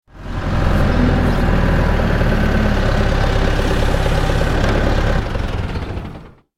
دانلود آهنگ تراکتور 5 از افکت صوتی حمل و نقل
جلوه های صوتی
دانلود صدای تراکتور 5 از ساعد نیوز با لینک مستقیم و کیفیت بالا